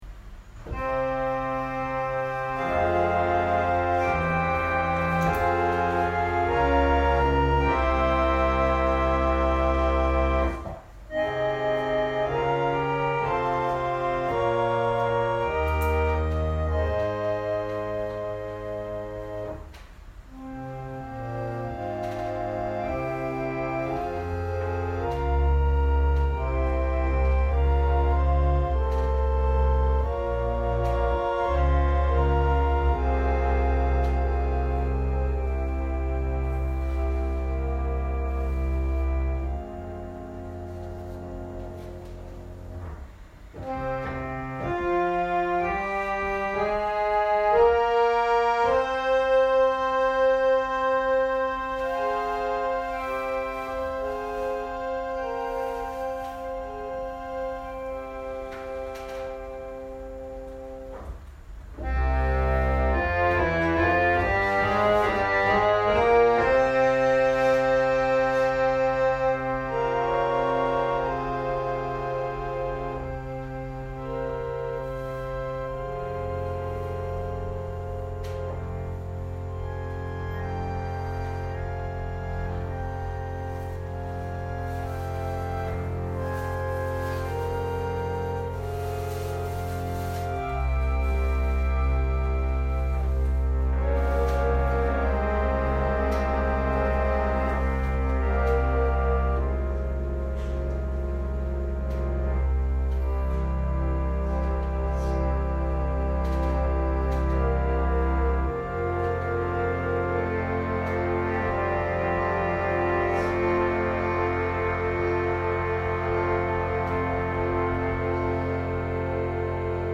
千間台教会。説教アーカイブ。